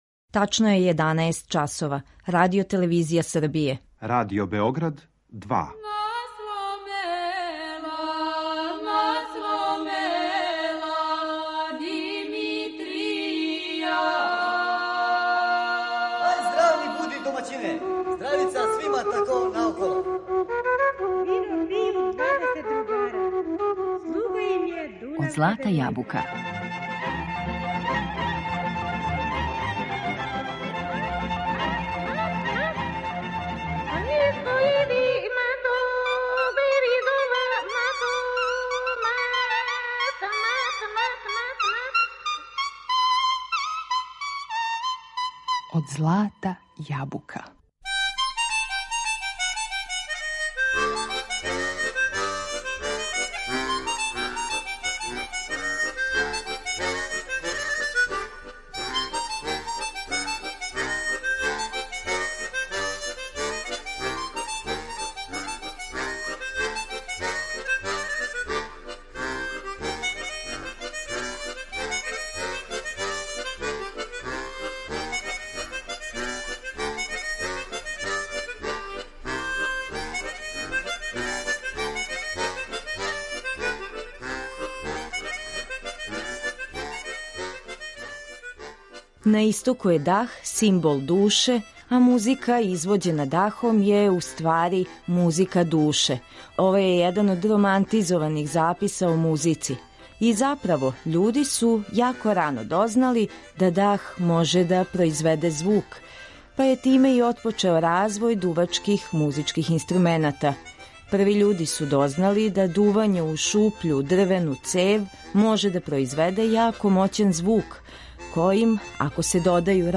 Усна хармоника
Неки од виртуоза који су на том инструменту одсвирали најлепше народне песме и мелодије за игру забележили су трајне снимке за Звучни архив Радио Београда. У данашњем издању емисије Од злата јабука уживамо у музицирању најбољих извођача на усној хармоници.